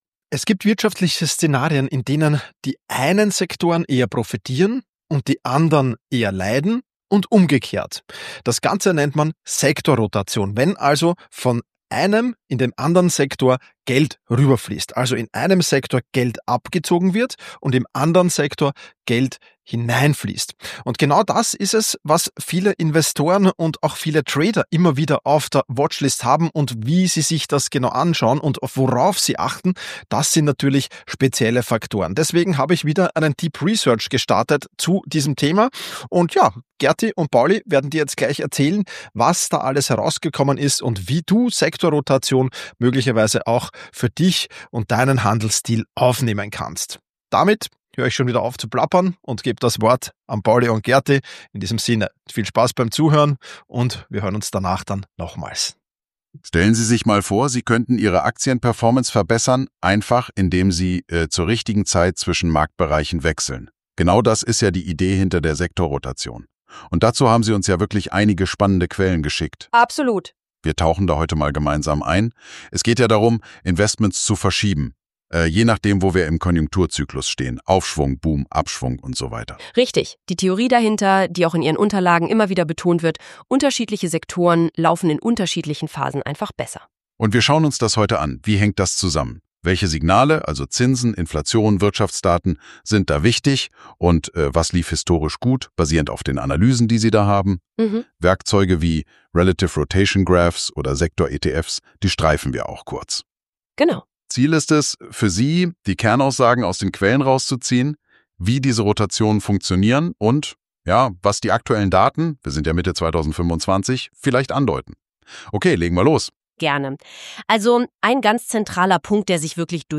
Meine Stimme wurde dafür geklont.